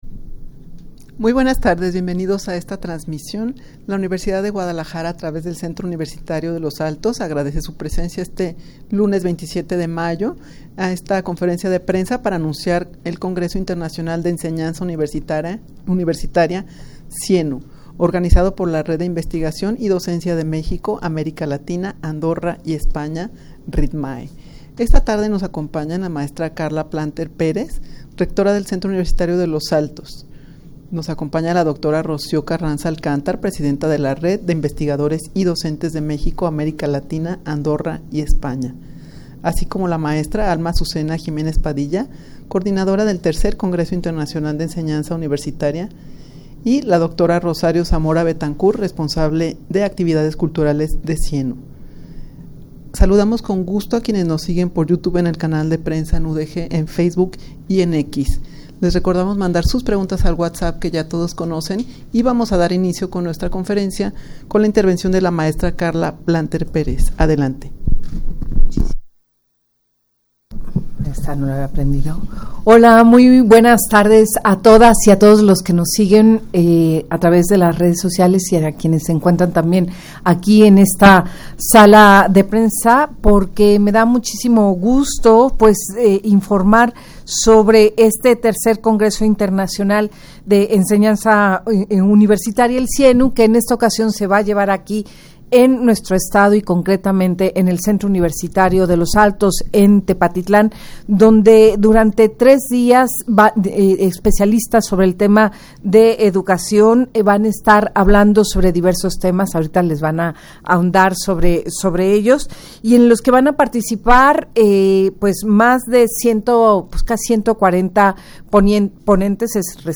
Audio de la Rueda de Prensa
rueda-de-prensa-para-anunciar-el-congreso-internacional-de-ensenanza-universitaria.mp3